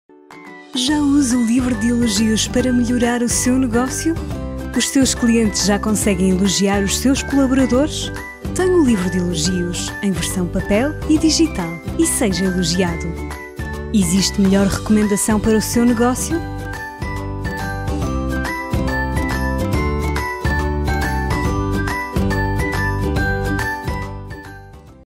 Portuguese Female Voiceover
Commercial
Commercials are dynamic, the content has the right intonation to highlight the message.